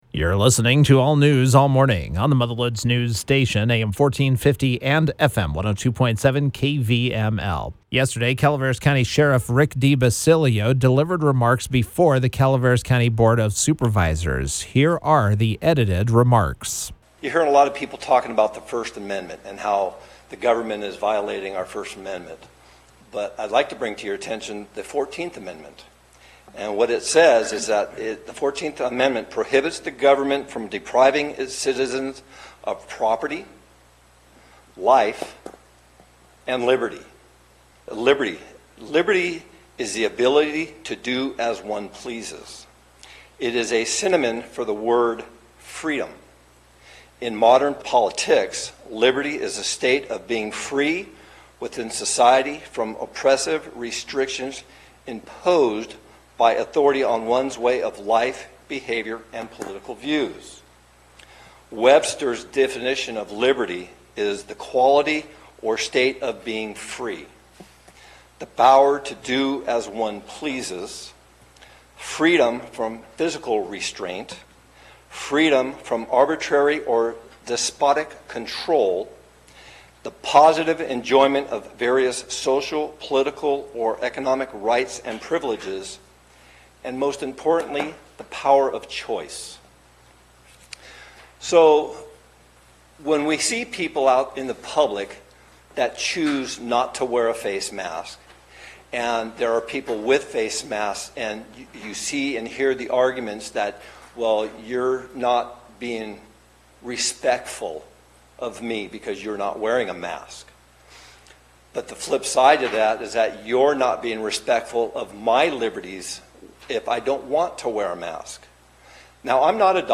On Tuesday, Calaveras Sheriff Rick DiBasilio spoke before the Calaveras County Board of Supervisors. The subject was facemasks.